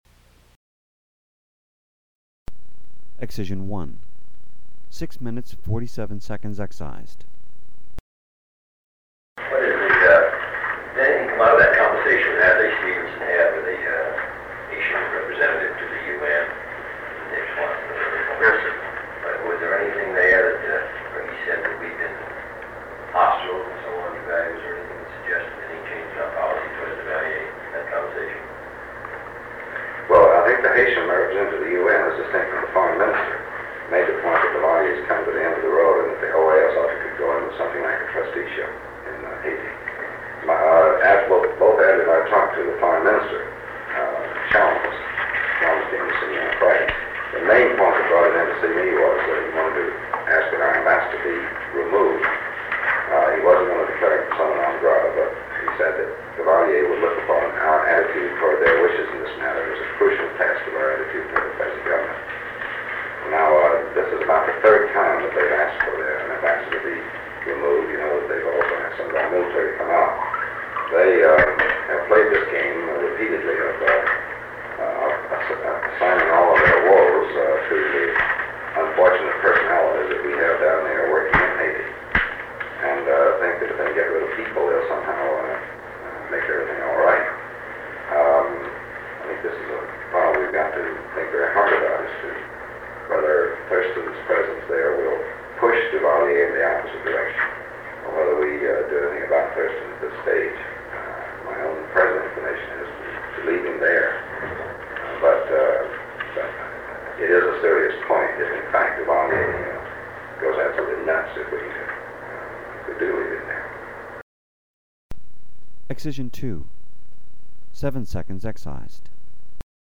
Haiti, 13 May 1963 John F. Kennedy Dean Rusk American Defense and Security Sound recording of a meeting between President John F. Kennedy, Secretary of State Dean Rusk, and others.
Please note that most of the Haiti meeting has been excised for national security reasons and will be submitted to the appropriate agencies for review. Seven segments of the recording totaling 18 minutes and 57 seconds have been removed in accordance with Section 3.4 (b) (1), (3) of Executive Order 12958.